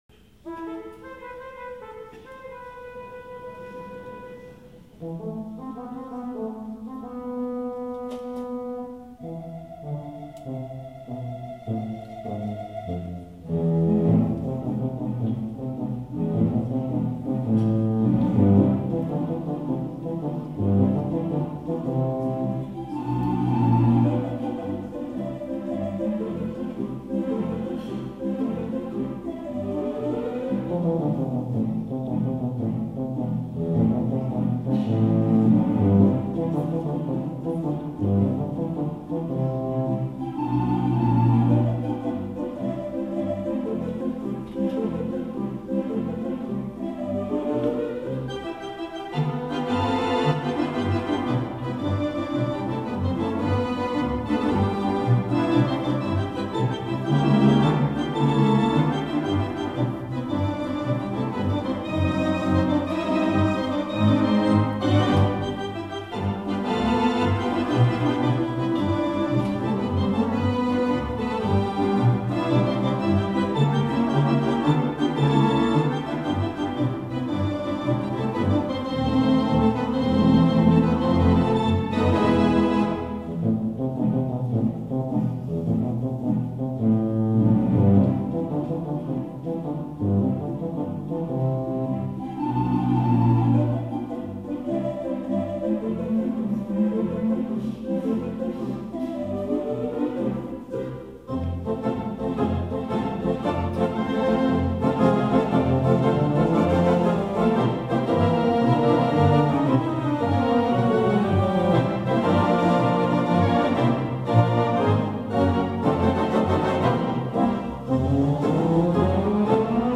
Recordings of the Capitol Wurlitzer